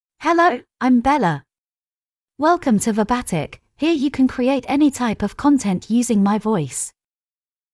FemaleEnglish (United Kingdom)
Bella is a female AI voice for English (United Kingdom).
Voice sample
Listen to Bella's female English voice.
Female